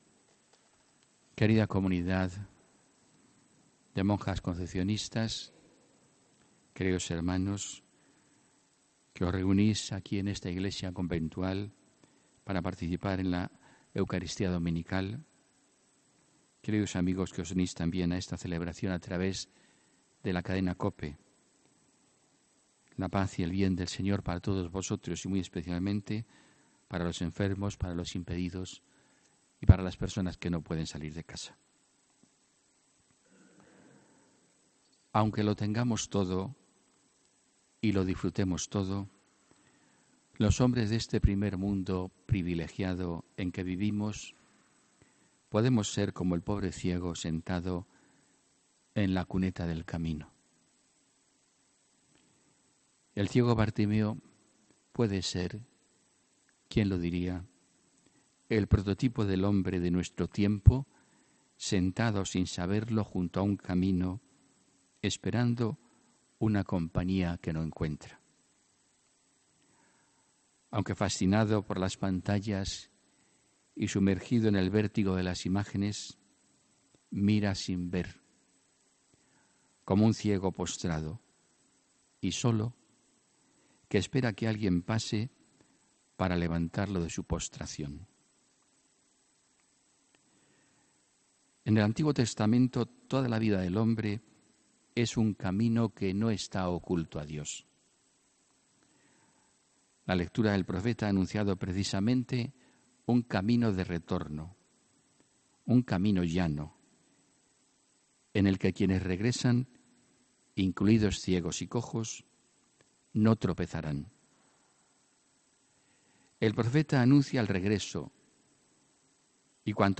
HOMILÍA 28 OCTUBRE 2018